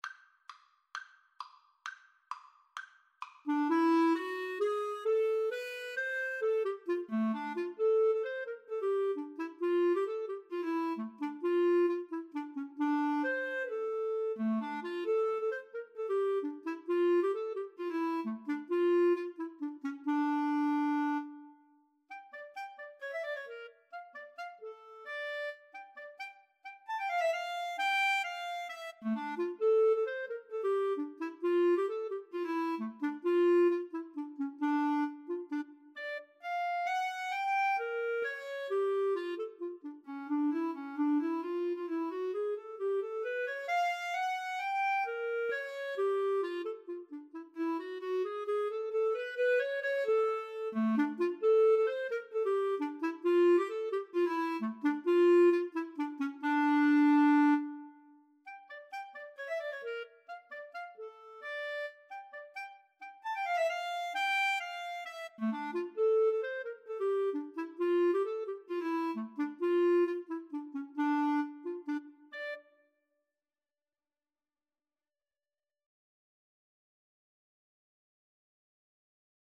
Free Sheet music for Clarinet-Bassoon Duet
D minor (Sounding Pitch) (View more D minor Music for Clarinet-Bassoon Duet )
2/4 (View more 2/4 Music)
Traditional (View more Traditional Clarinet-Bassoon Duet Music)